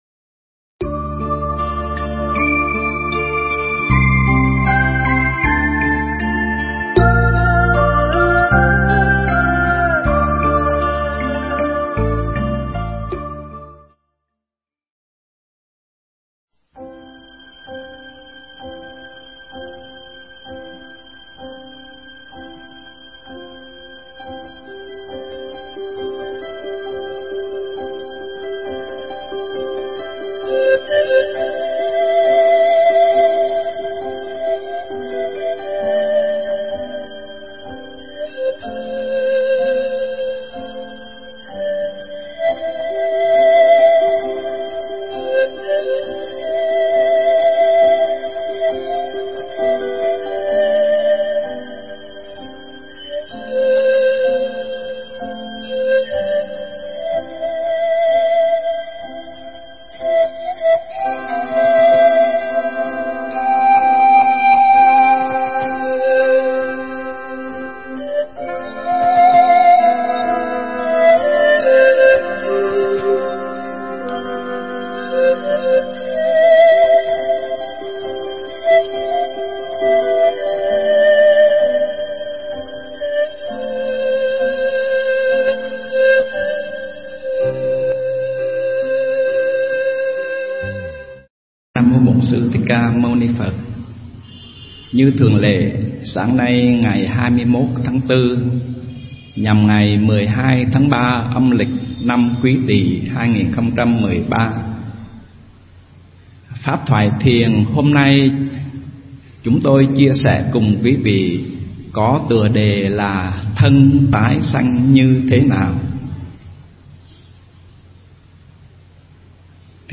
Nghe Mp3 thuyết pháp Thân Tái Sanh Thế Nào